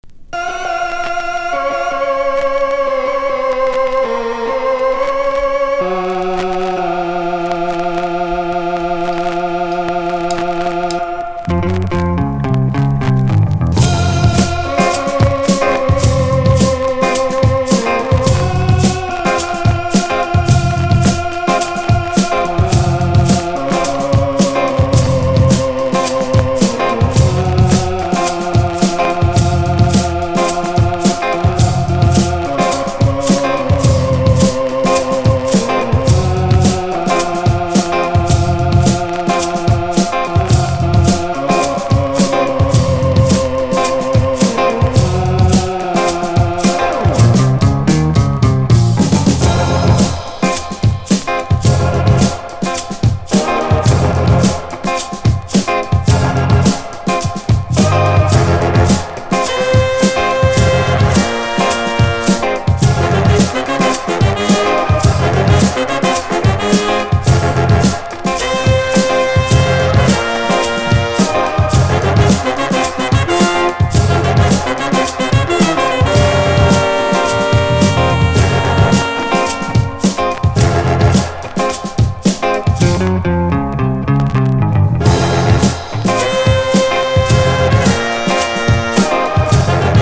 ムーグシンセ使いのダーク・サイケデリック・ファンク！
# FUNK / DEEP FUNK